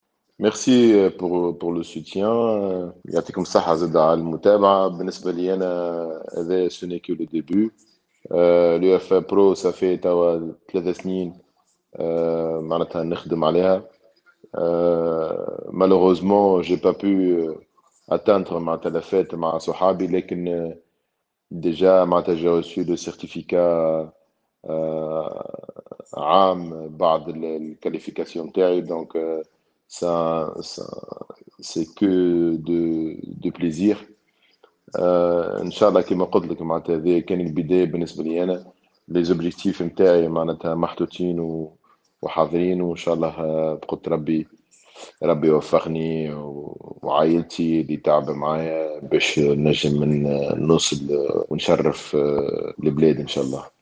وعبر الجعايدي في حواره الخاص بالجوهرة أف أم عن سعادته بنيل هذه الشهادة مؤكدا انه قد عمل لمدة ثلاث سنوات من أجل تحقيق هذا الهدف الذ سيخول له اتخاذ خطوة مهمة في مسيرته التدريبية، مؤكدا سعيه المتواصل لتشريف الراية الوطنية خارج تونس.